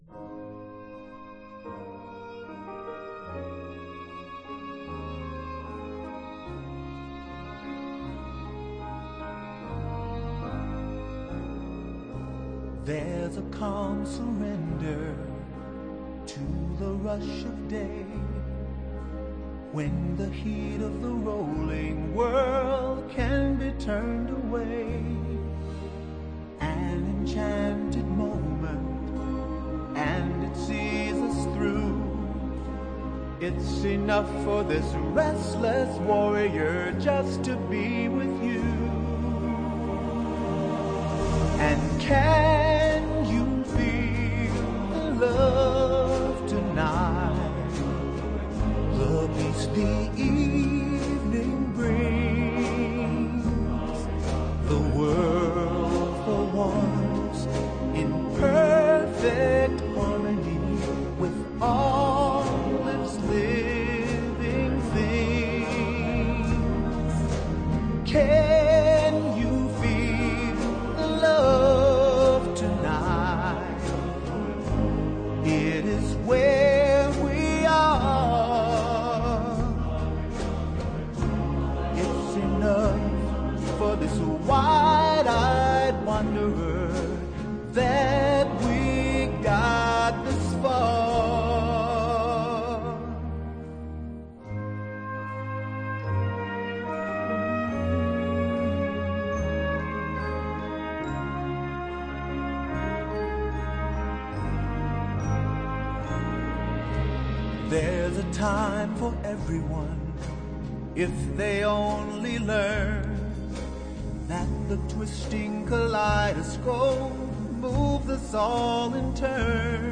这张唱片采用最新20bit以及环绕音效录音技术，音质一流！